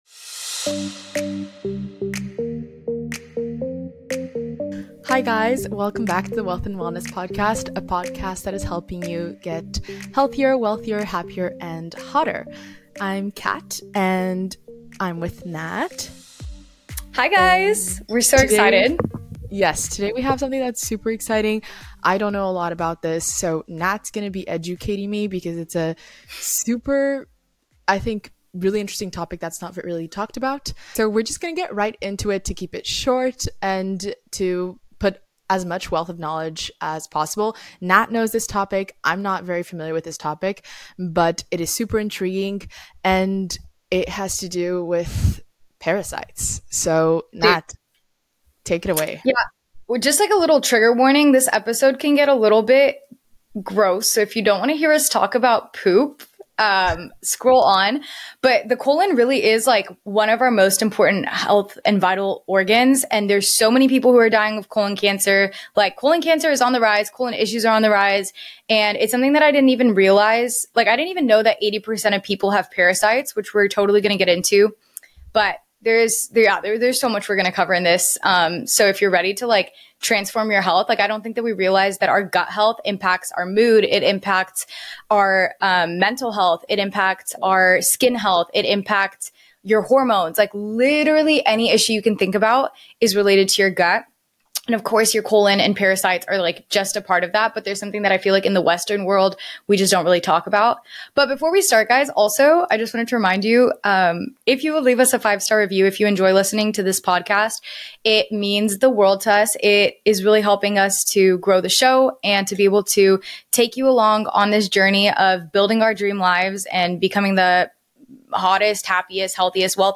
In this episode, we delve into women's health, including hormone balance, diet, exercise, sleep, and stress management. We'll share tips for optimizing hormone health naturally and minimizing exposure to endocrine disruptors. Join us for an insightful conversation packed with valuable information to help you take charge of your health and well-being.